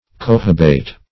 Search Result for " cohobate" : The Collaborative International Dictionary of English v.0.48: Cohobate \Co`ho*bate\, v. t. [imp.